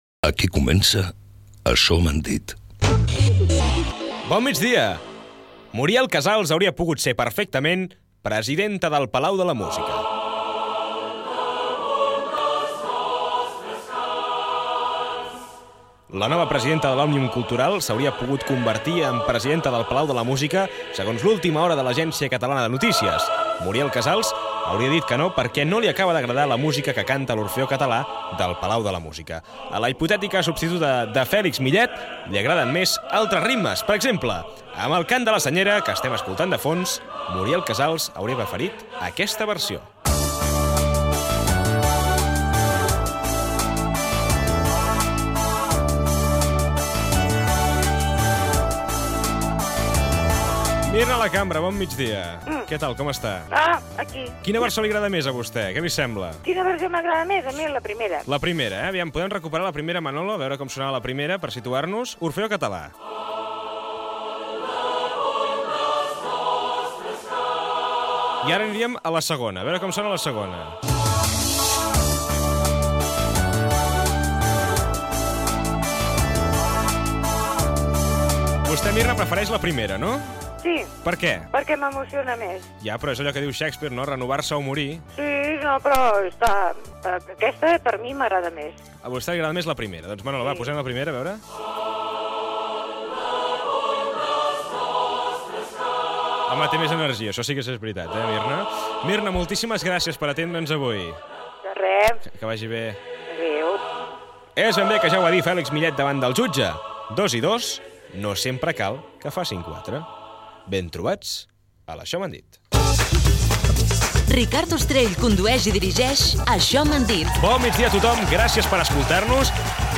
Indicatiu del programa, comentari sobre la presidenta d'Òmnium Cultural Muriel Casals, pregunta a una oïdora, equip, indicatiu del programa, la figura de Muriel Casals i la funció d'Òmnium Cultural, formes de contactar amb el programa, entrevista a Muriel Casals, preguntant pel seu nom, la situació al Palau de la Música (degut al cas Millet), la seva formació d'economista